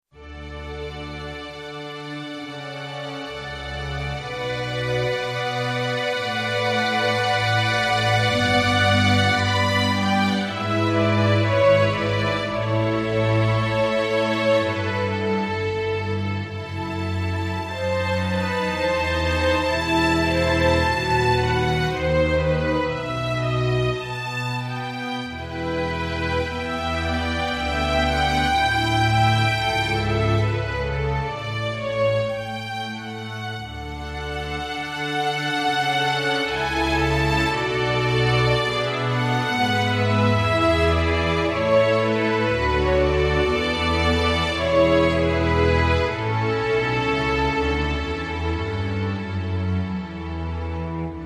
クラシック音楽の曲名